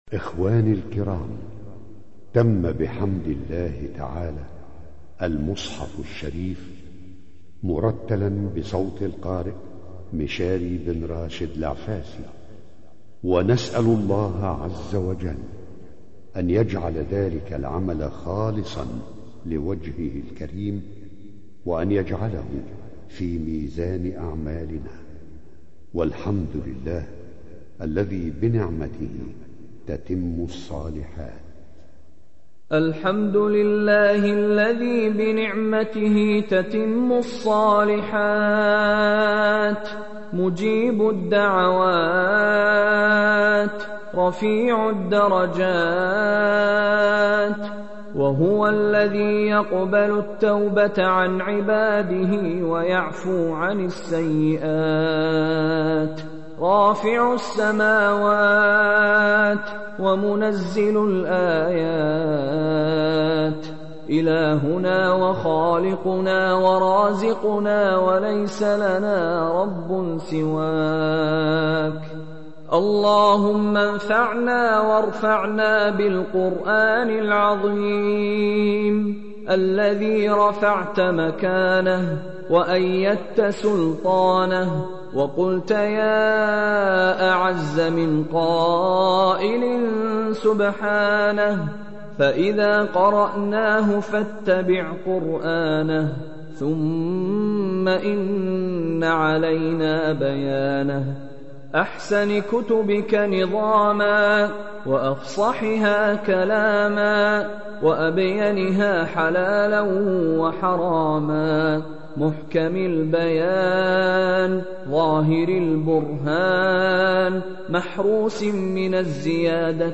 Чтение Корана > МИШАРИ РАШИД